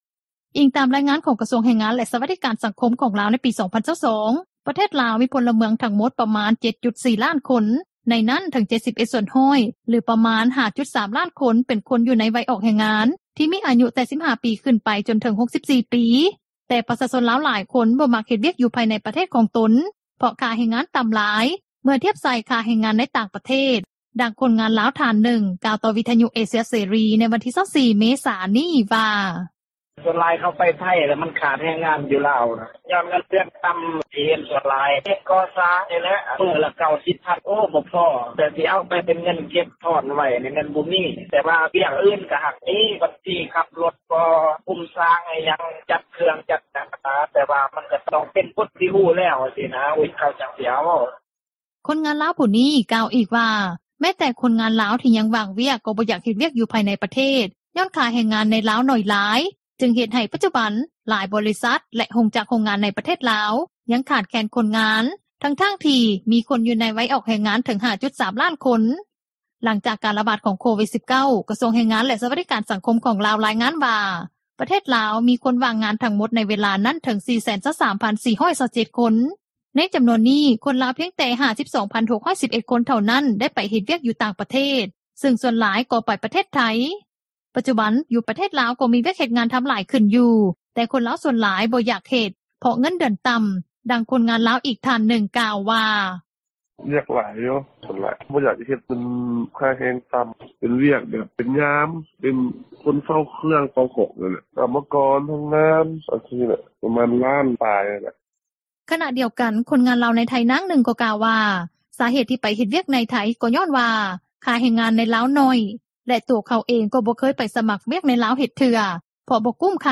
ດັ່ງຄົນງານລາວ ອີກທ່ານນຶ່ງກ່າວວ່າ:
ດັ່ງຊາວລາວ ທ່ານນຶ່ງ ກ່າວວ່າ: